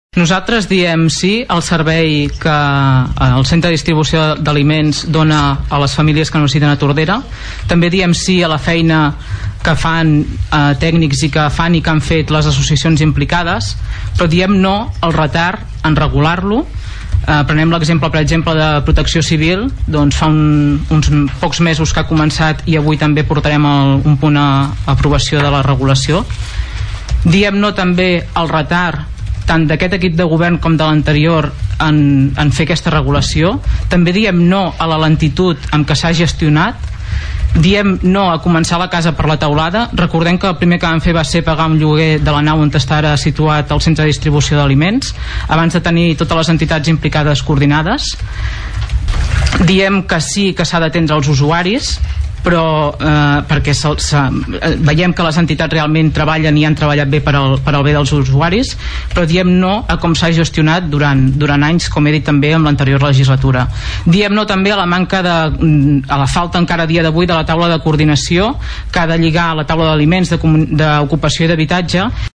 Escoltem la regidora Anna Serra.